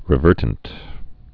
re·ver·tant
(rĭ-vûrtnt)